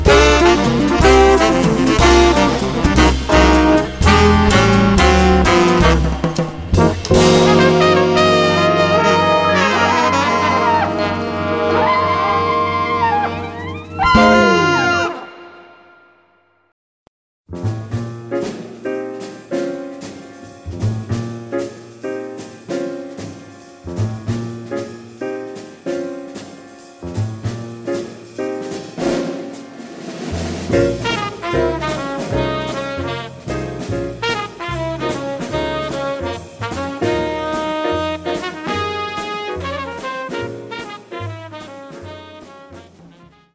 Tema musical